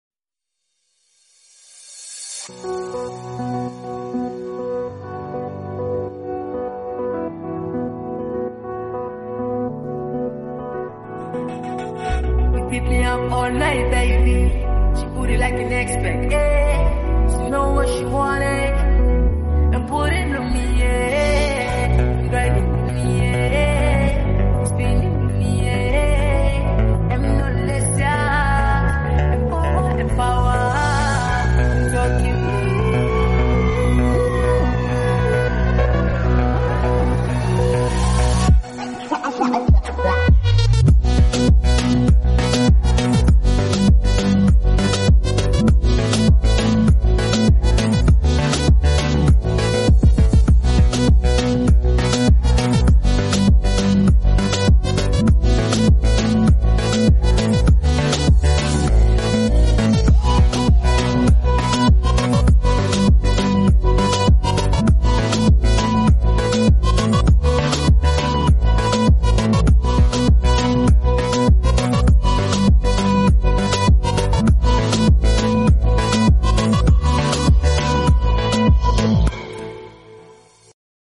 swing bounce style